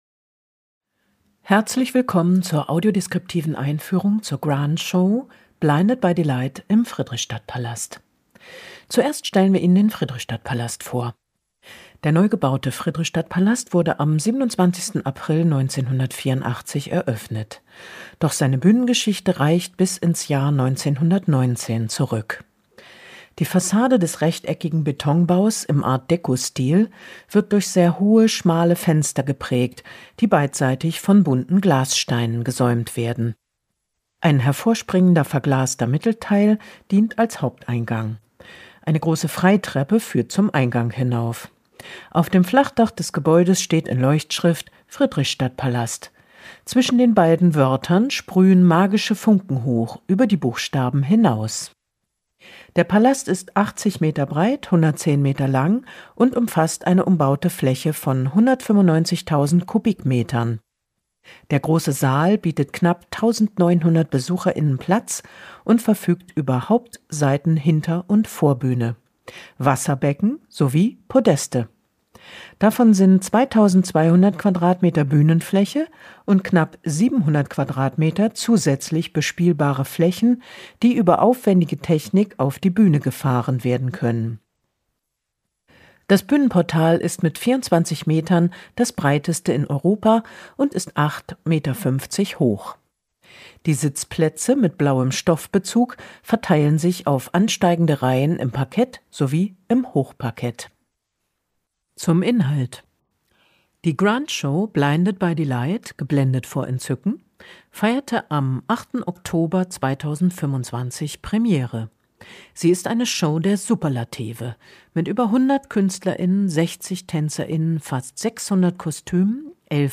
Audiodeskriptive Einführung "Blinded by delight"